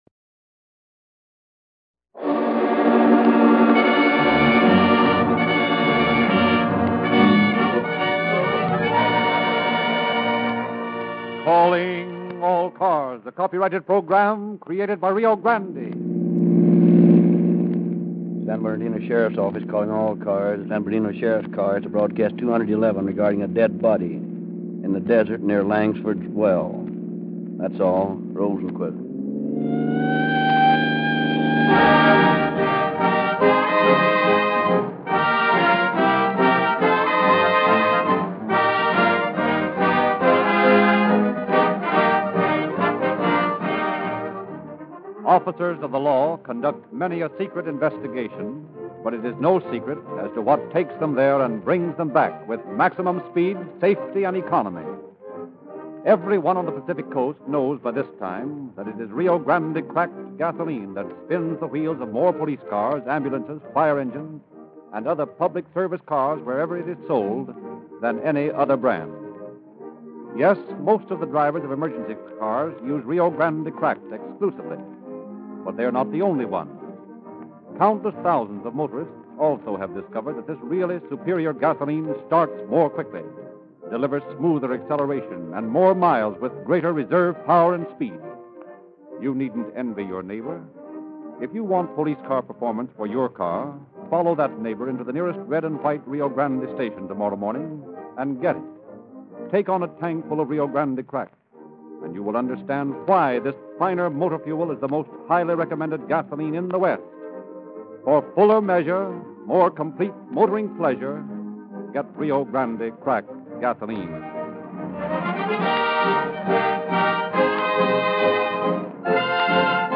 Calling All Cars Radio Program